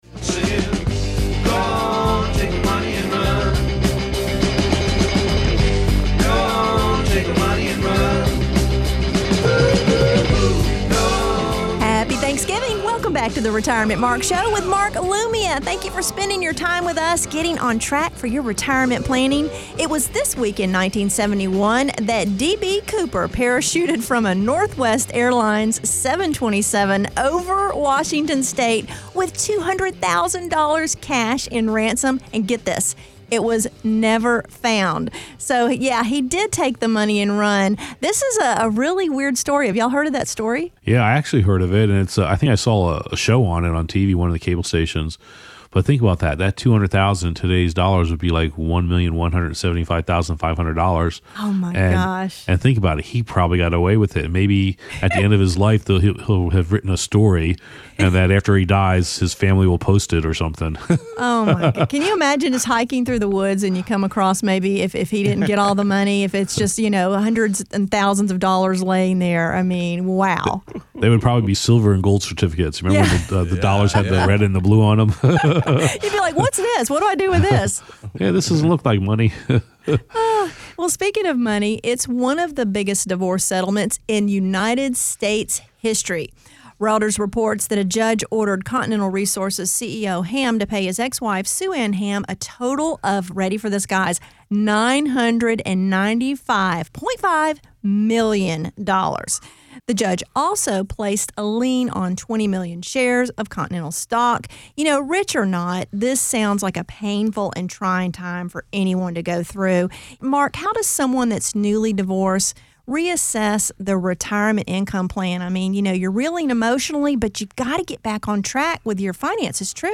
Radio Show 11/26/14 Segment 4